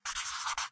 Minecraft Version Minecraft Version snapshot Latest Release | Latest Snapshot snapshot / assets / minecraft / sounds / ui / cartography_table / drawmap1.ogg Compare With Compare With Latest Release | Latest Snapshot
drawmap1.ogg